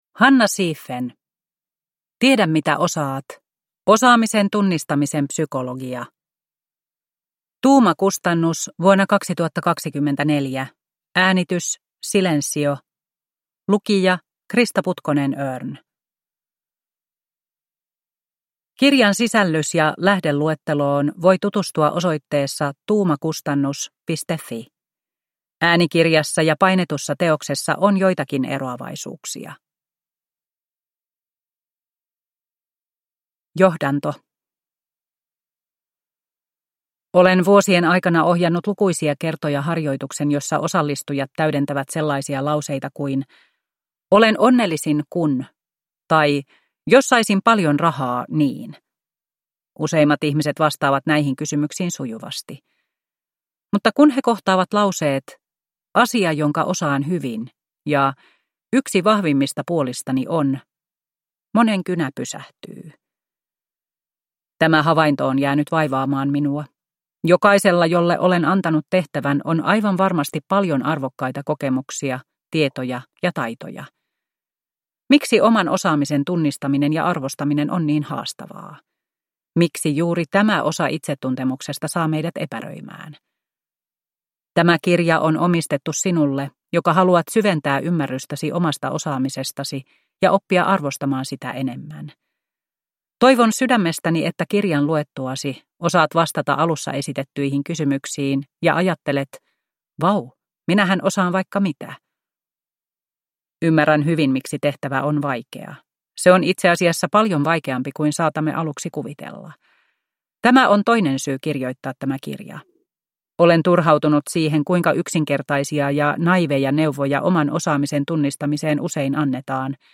Tiedä mitä osaat – Ljudbok